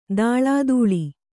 ♪ dāḷādūḷi